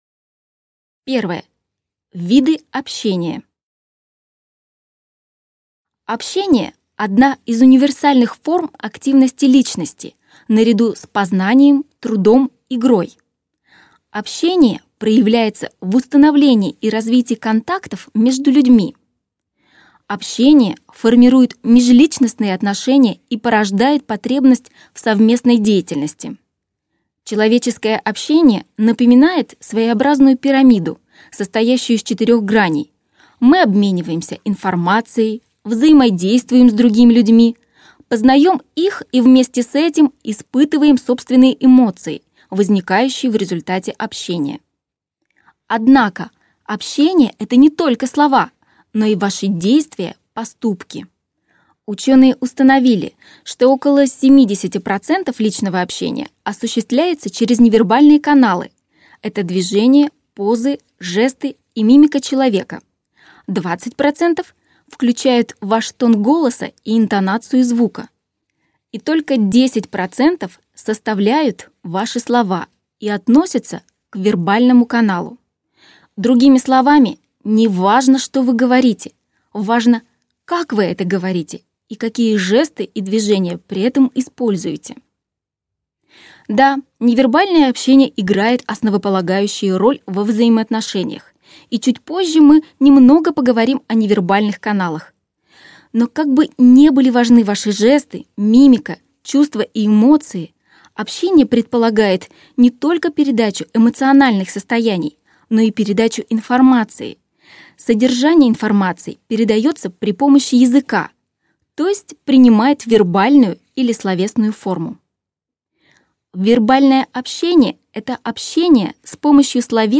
Аудиокнига Хочу говорить красиво! Техники общения | Библиотека аудиокниг